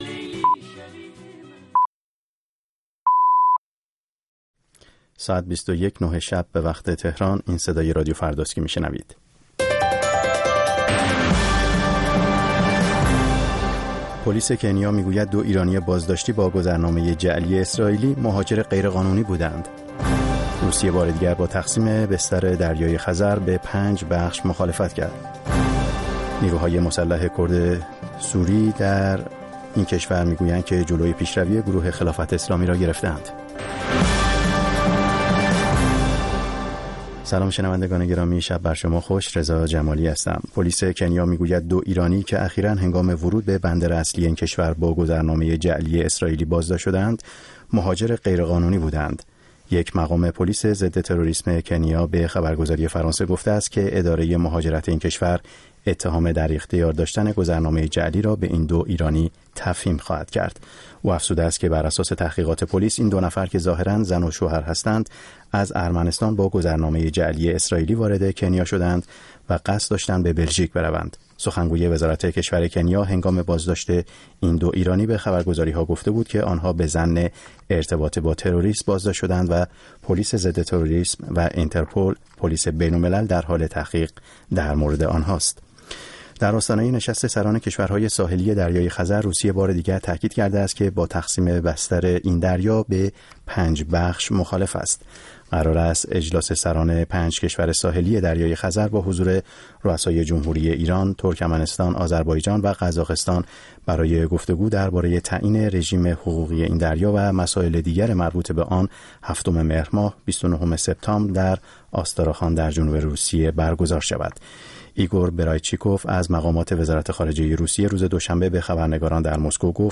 روی خط برنامه ای است برای شنیدن نظرات شما. با همفکری شما هر شب یک موضوع انتخاب می کنیم و شما می توانید از طریق تلفن، اسکایپ، فیس بوک یا ایمیل، به صورت زنده در بحث ما شرکت کنید.